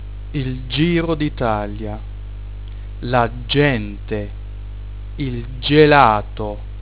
6.) "g" vor "e" od. "i" (Giro dŽItalia, gente, gelato)
[dʒ] (stimmhaft)